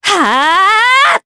Yanne-Vox_Casting4_jp.wav